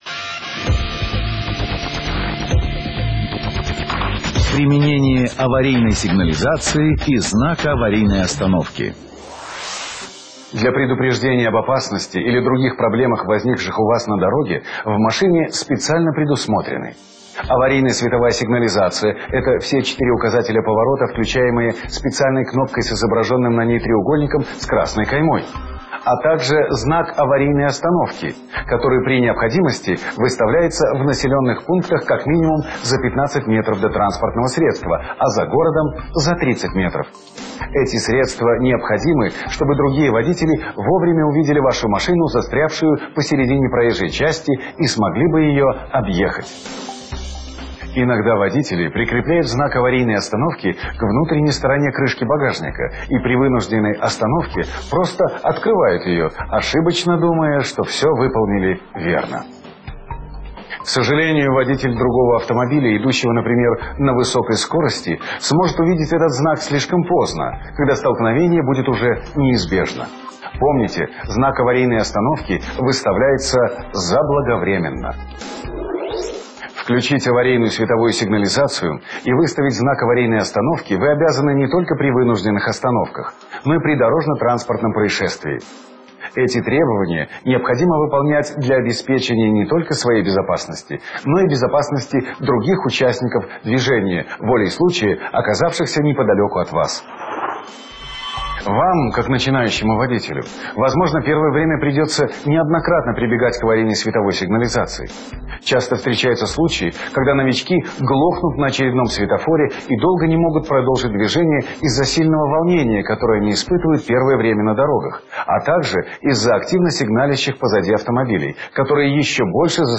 Аудіо лекції